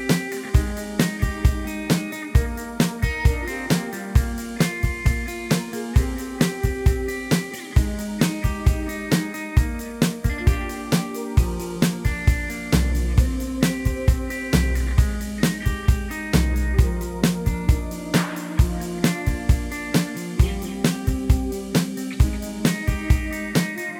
Minus Bass Pop (2010s) 3:18 Buy £1.50